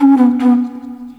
Flute 51-10.wav